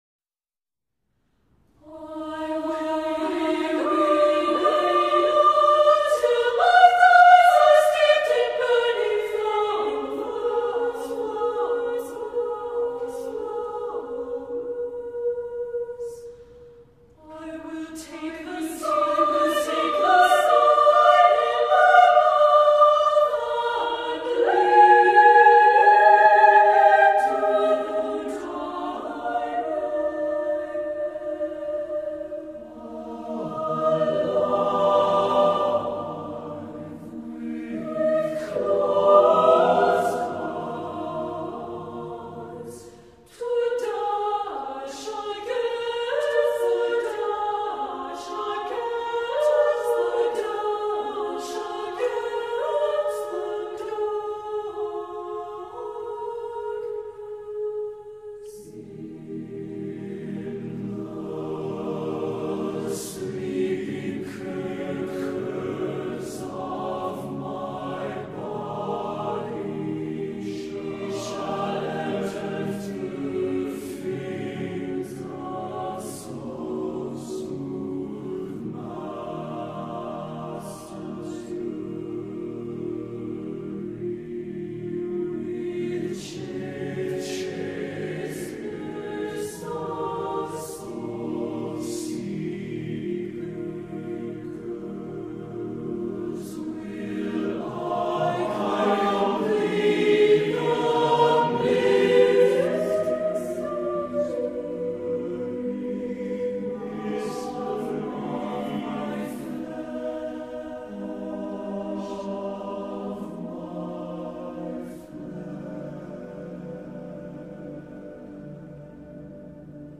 Accompaniment:      A Cappella
Music Category:      Choral
cries out with lush, neo-romantic harmonies.